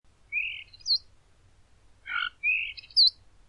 Estornino sagrado (Gracula religiosa)
Comportamiento : Excelentes imitadores de sonidos.